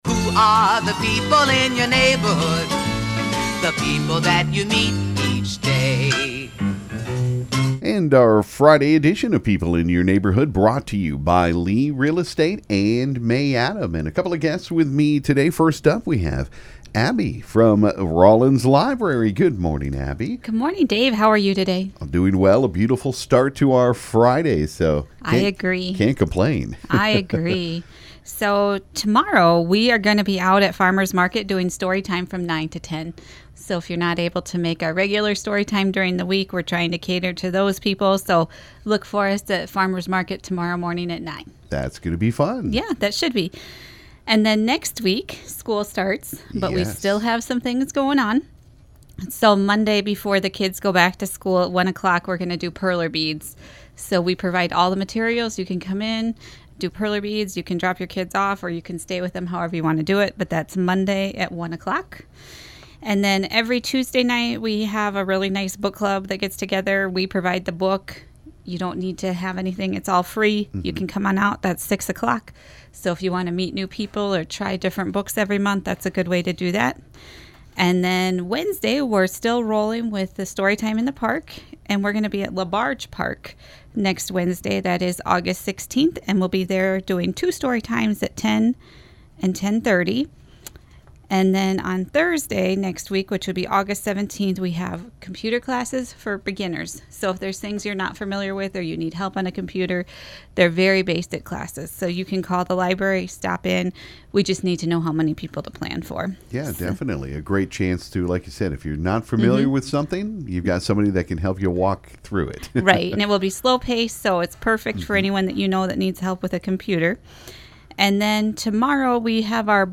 This morning on People In Your Neighborhood there were a couple of great ladies joining us.